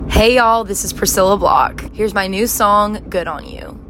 LINER Priscilla Block (Good On You) 2